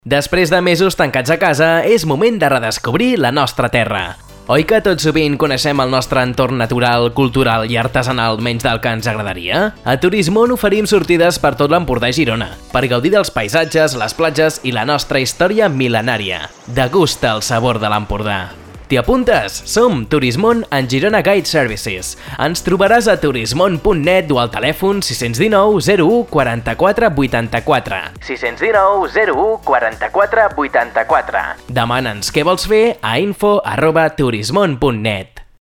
Anunci-Turismon-1.mp3